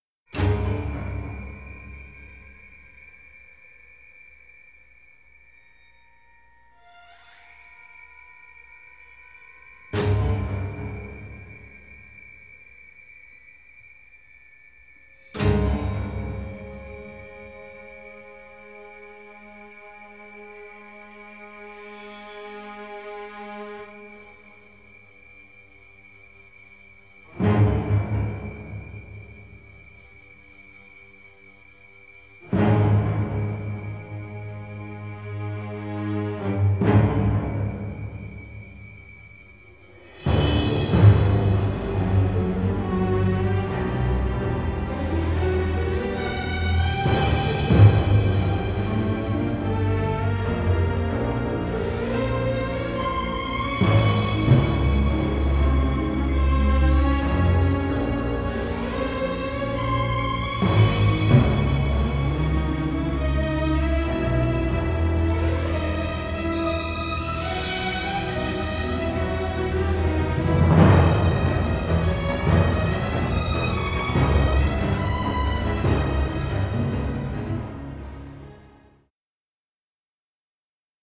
dark thriller score
original stereo session mixes